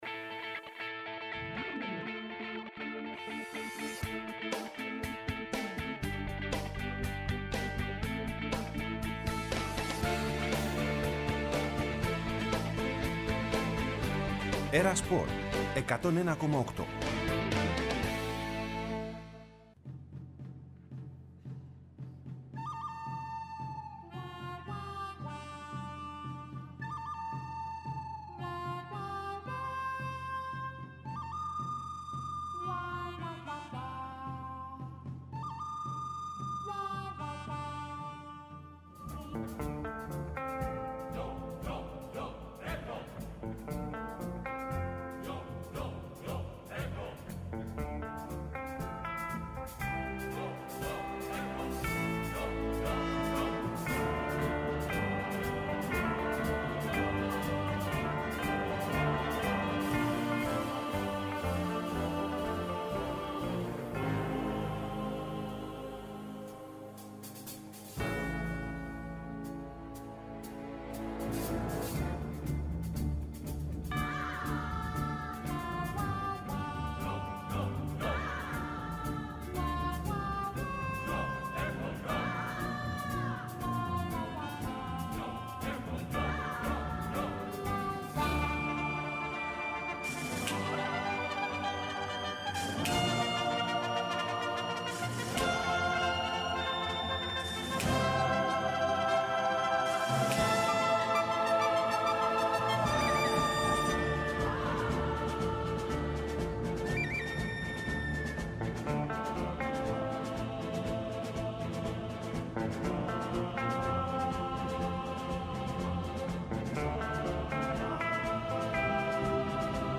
Ενημέρωση από τους ρεπόρτερ του Ολυμπιακού, του Παναθηναϊκού, της ΑΕΚ, του ΠΑΟΚ και του Άρη.